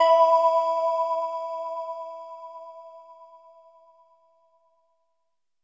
LEAD E4.wav